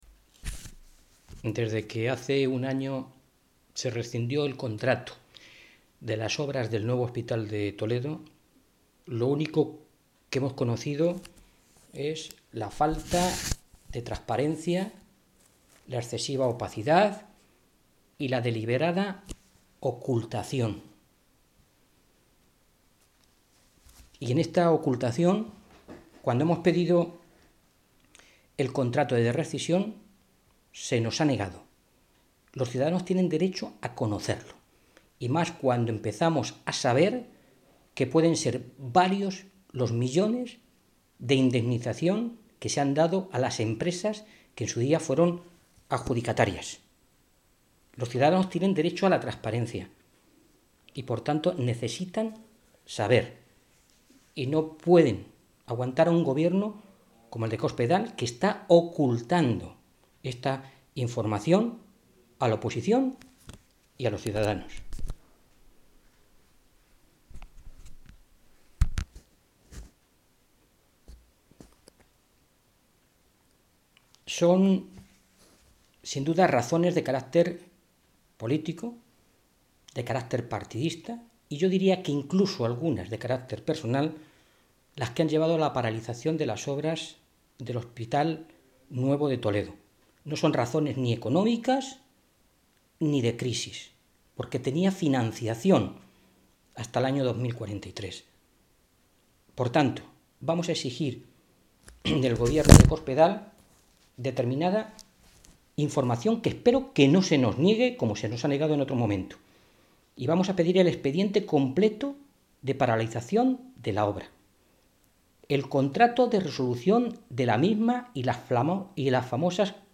Mora se pronunciaba de esta manera esta mañana, en Toledo, en una comparecencia ante los medios de comunicación en la que volvía a denunciar la “opacidad y absoluta falta de transparencia del Gobierno de Cospedal en este asunto”.
Cortes de audio de la rueda de prensa